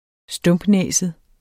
Udtale [ ˈsdɔmbˌnεˀsəð ]